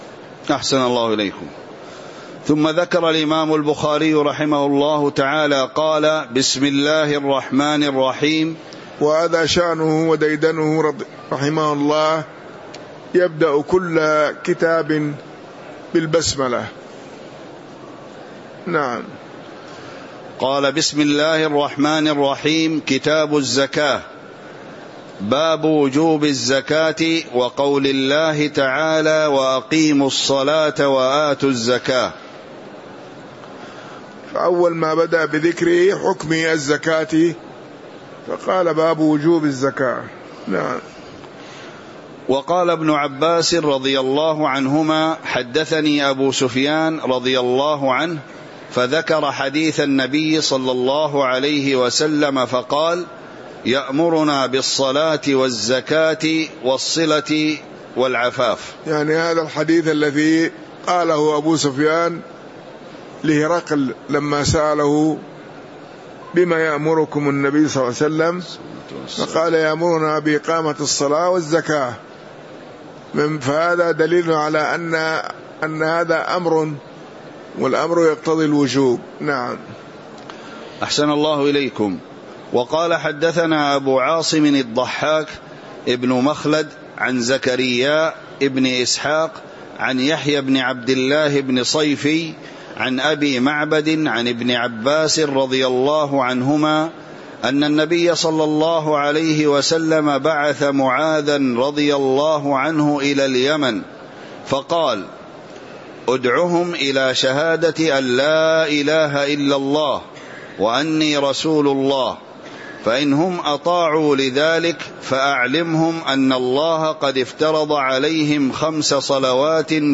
تاريخ النشر ٢٩ صفر ١٤٤٤ هـ المكان: المسجد النبوي الشيخ